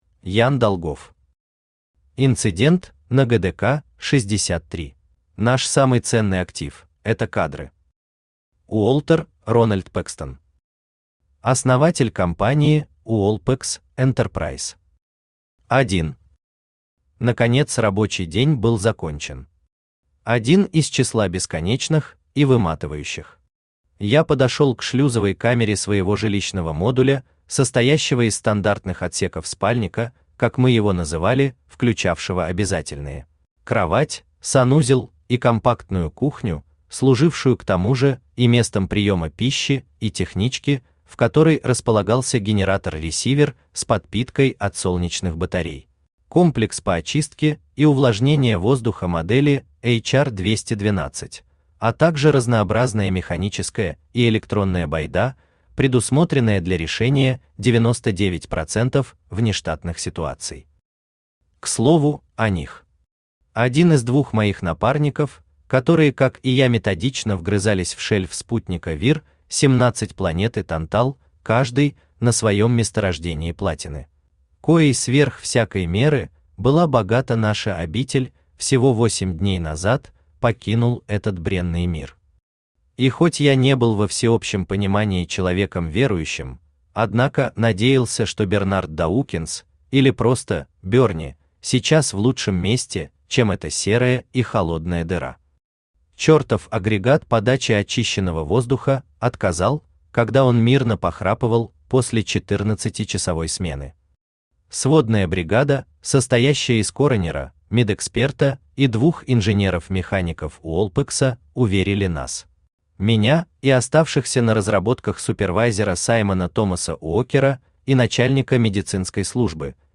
Аудиокнига Инцидент на ГДК 63 | Библиотека аудиокниг
Aудиокнига Инцидент на ГДК 63 Автор Ян Долгов Читает аудиокнигу Авточтец ЛитРес.